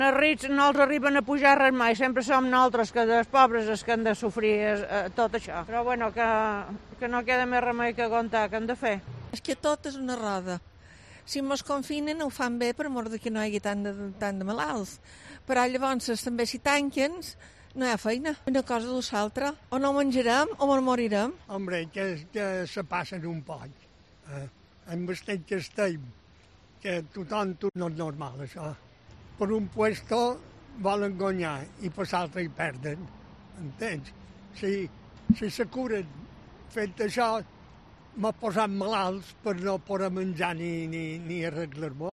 Hemos salido a la calle para preguntar a los ciudadanos que les parecen el incremento de la presión fiscal del Gobierno en plena crisis y esto es lo que nos han contestado.
Ciudadanos opinan sobre la subida de impuestos